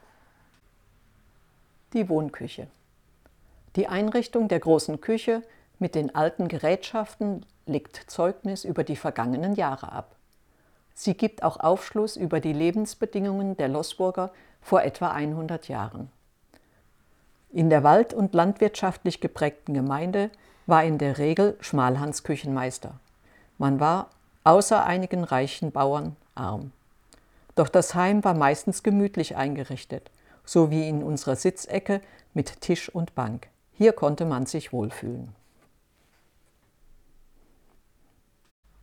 Erleben Sie einen interessanten Rundgang durch unser Heimatmuseum "Altes Rathaus" in Loßburg und lassen Sie sich mit unserem Audioguide durch Raum und Zeit begleiten.